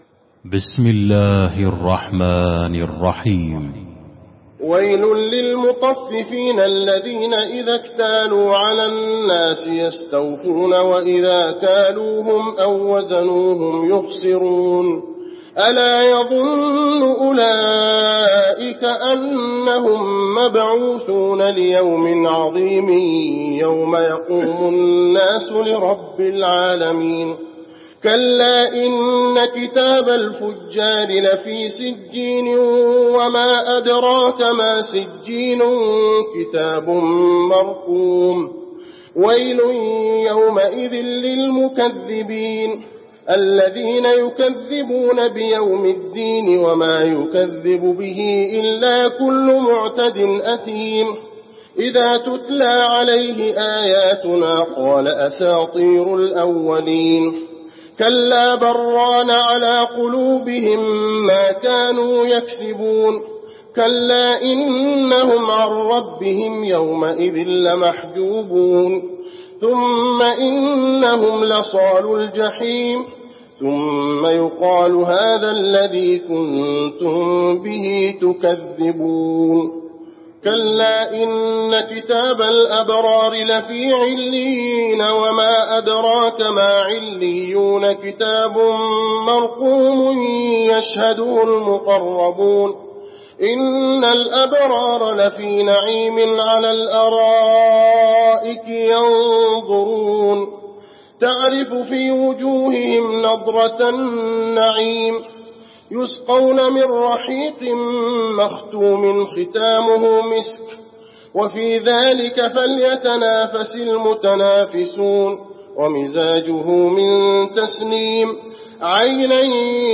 المكان: المسجد النبوي المطففين